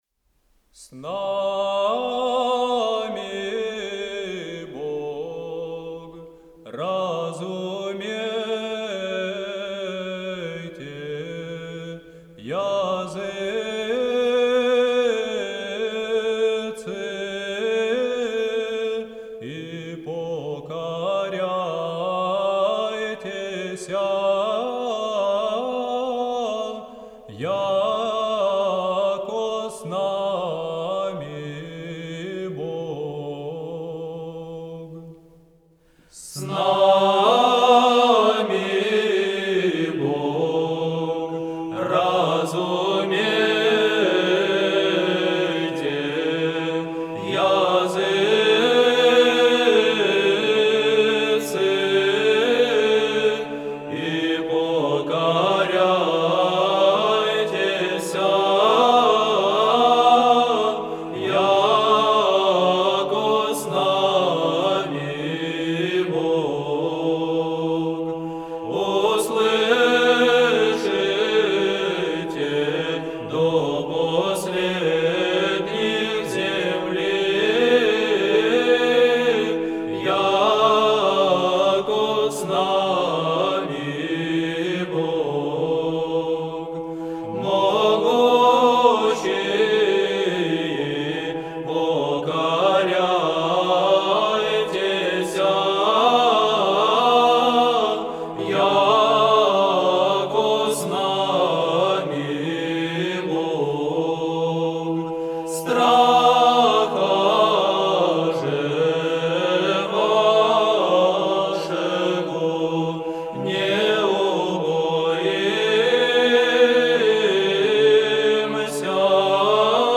Знаменный распев.